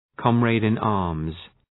Προφορά
{,kɒmrædın’ɑ:rmz}